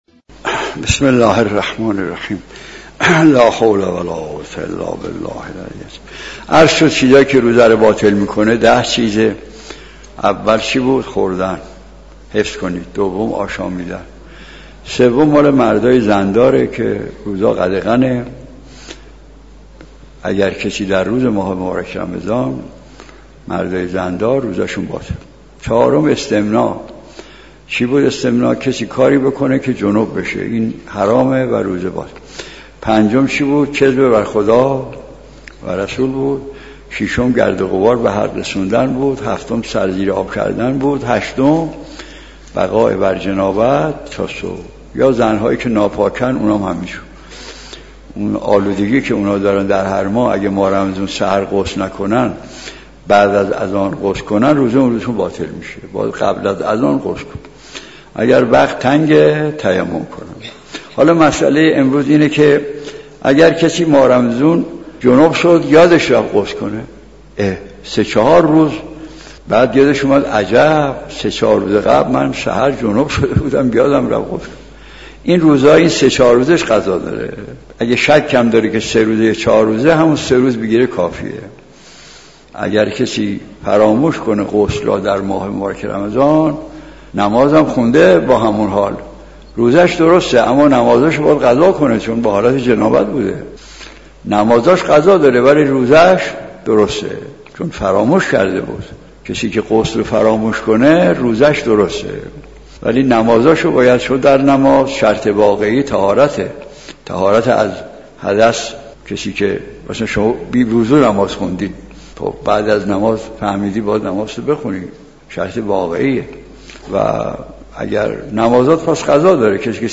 به گزارش خبرنگار فرهنگ دفاع‌پرس ، مرحوم آیت‌الله «احمد مجتهدی تهرانی» از علمای بزرگ اخلاق و حدیث بود که جلسات وعظ وی به دلیل سادگی بیان و گفتار همواره مورد استقبال عموم مردم بود و همه مخاطبان می‌توانستند از آن بهره بگیرند.